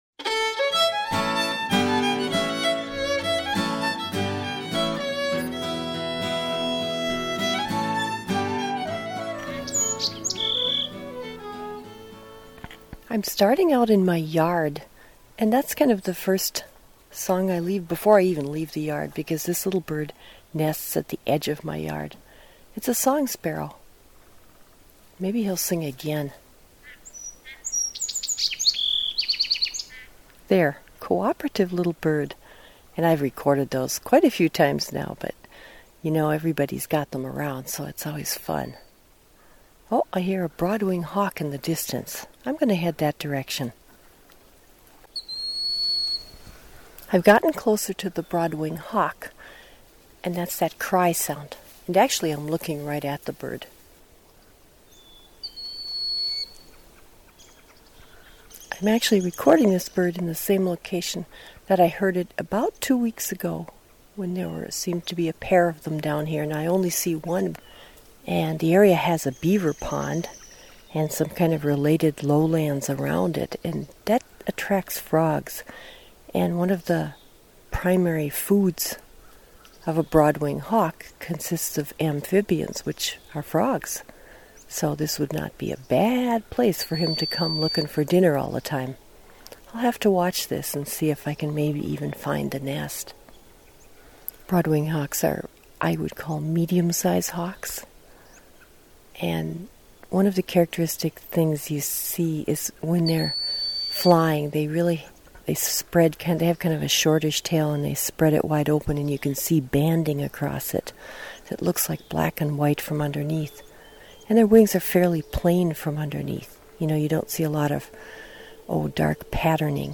Field Notes: Broad winged hawk